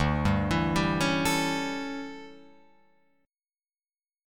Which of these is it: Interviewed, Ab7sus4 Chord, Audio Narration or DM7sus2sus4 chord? DM7sus2sus4 chord